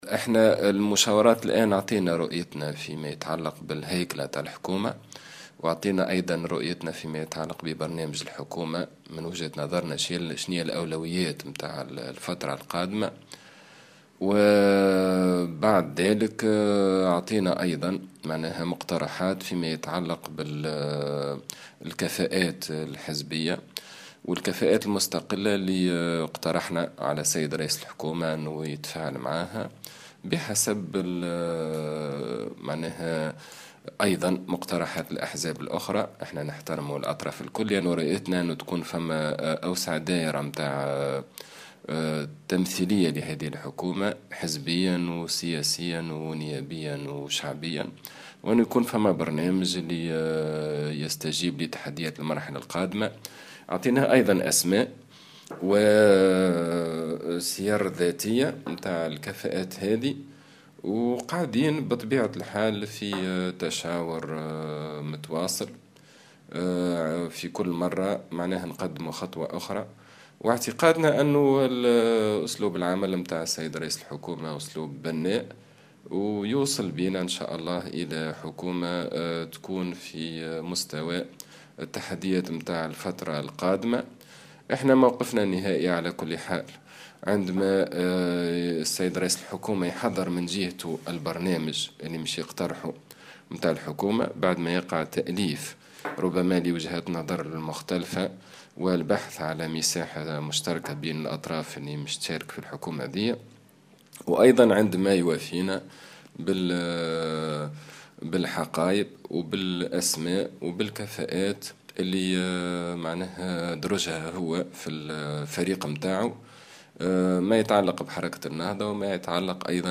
Imed Hammami, dirigeant d'Ennahdha a déclaré au micro de Jawhara Fm que son parti a présenté ce lundi matin au chef du gouvernement désigné, Habib Essid, sa vision sur la structuration du nouveau gouvernement ainsi que sur les priorités du programme gouvernemental.